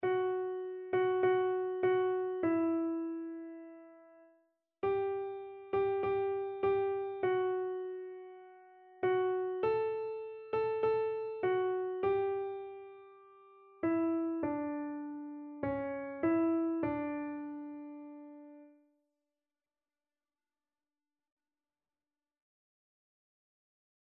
Christian Christian Keyboard Sheet Music Hear Our Prayer, O Lord
Free Sheet music for Keyboard (Melody and Chords)
4/4 (View more 4/4 Music)
D major (Sounding Pitch) (View more D major Music for Keyboard )
Keyboard  (View more Easy Keyboard Music)
Classical (View more Classical Keyboard Music)